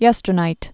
(yĕstər-nīt)